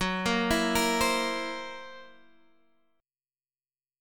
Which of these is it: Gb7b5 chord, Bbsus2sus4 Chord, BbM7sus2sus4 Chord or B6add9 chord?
Gb7b5 chord